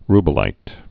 (rbə-līt, r-bĕlīt)